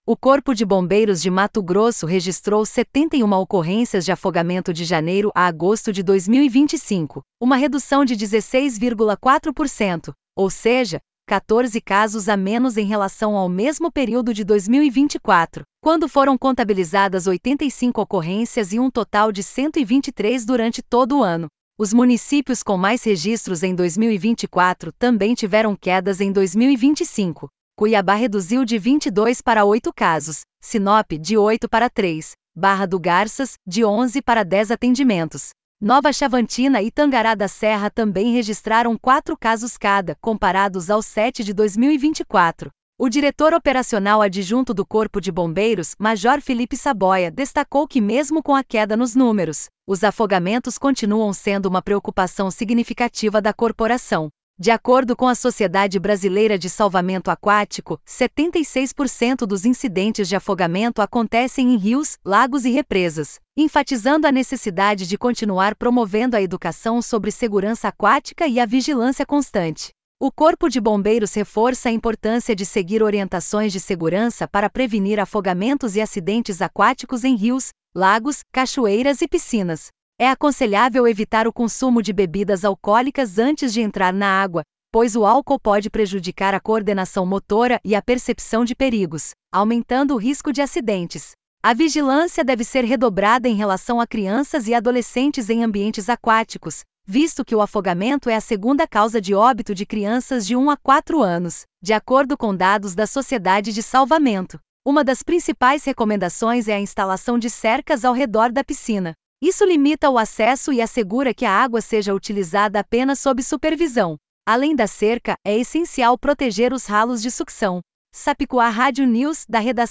Boletins de MT 14 out, 2025